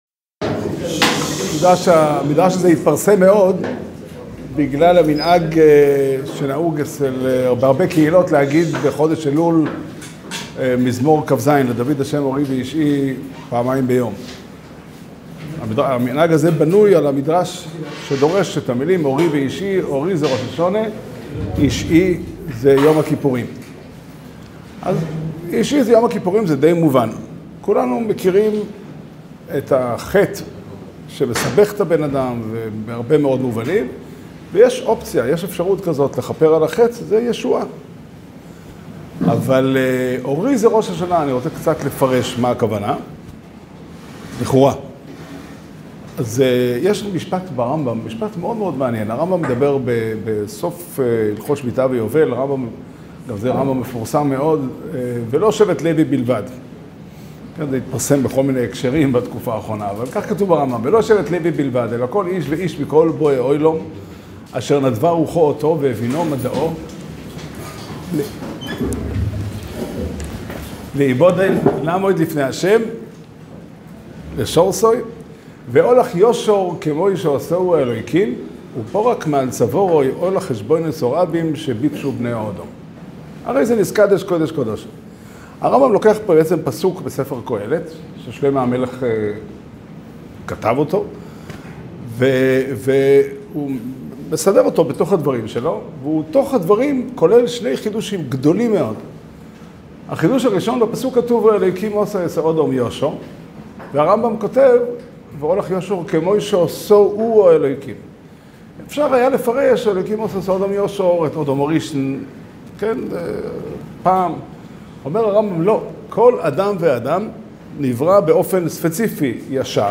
שיעור שנמסר בקהילת 'חלקי בקהילתי' - כרמיאל בתאריך ט"ו אלול תשפ"ד